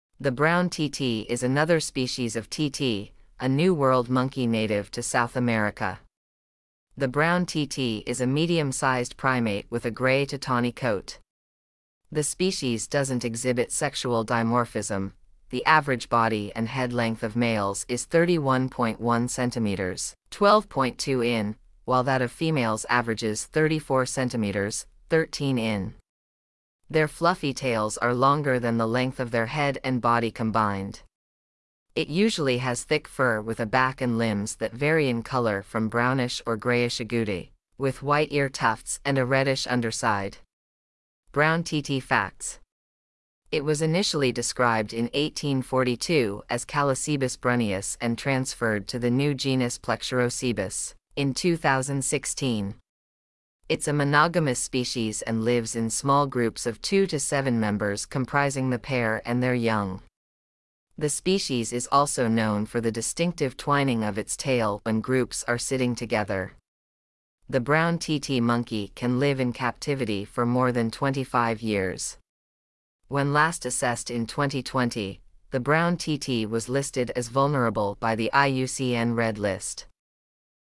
Brown Titi
Brown-Titi.mp3